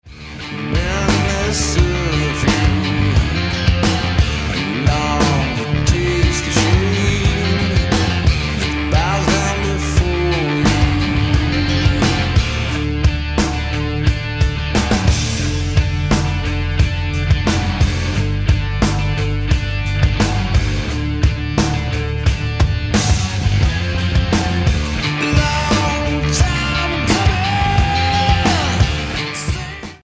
voc, gtr
drums
bass